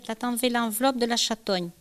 Localisation Notre-Dame-de-Riez
Catégorie Locution